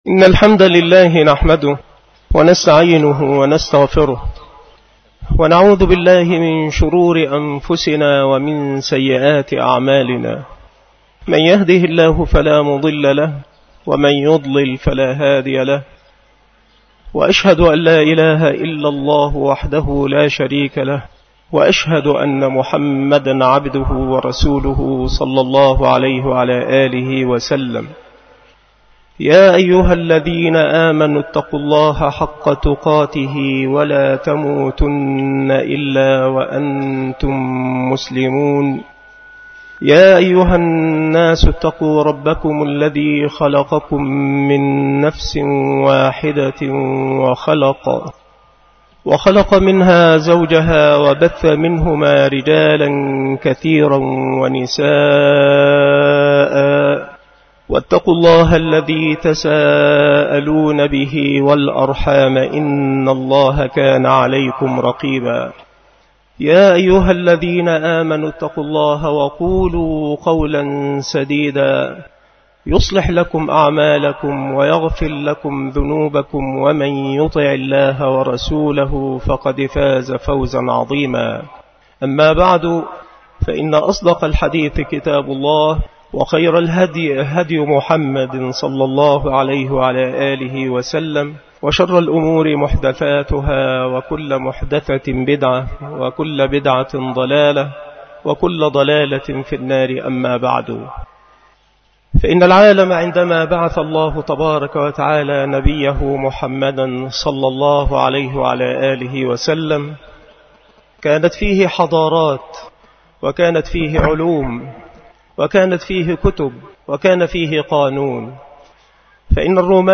خطبة عيد الفطر لعام 1429هـ
خطب العيدين
مكان إلقاء هذه المحاضرة بمركز شباب سبك الأحد - أشمون - محافظة المنوفية - مصر